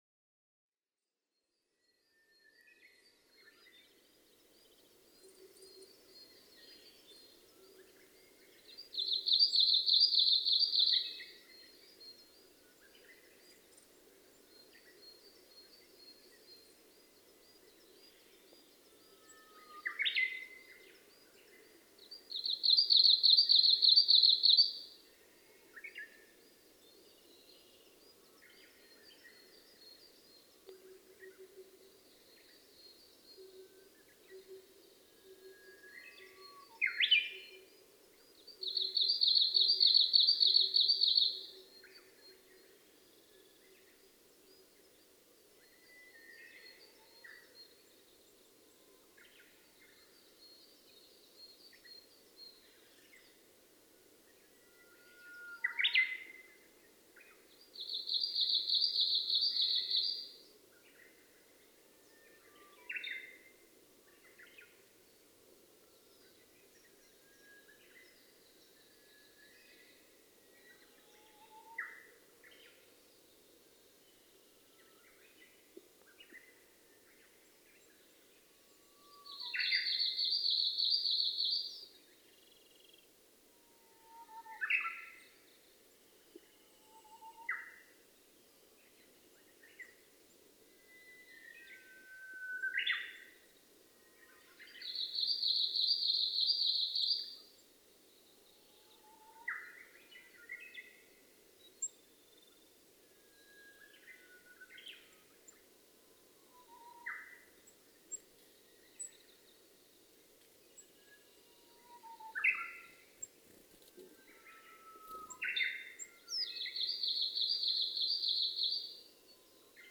メボソムシクイ　Phylloscopus borealisウグイス科
群馬県片品村菅沼　alt=1770m
MPEG Audio Layer3 FILE 128K 　2'54''Rec: EDIROL R-09
Mic: Sound Professionals SP-TFB-2  Binaural Souce
後半、ヒガラらしい鳥が耳元まで来て羽音をたてました。
他の自然音：ウグイス、ヒガラ、ルリビタキ、ツツドリ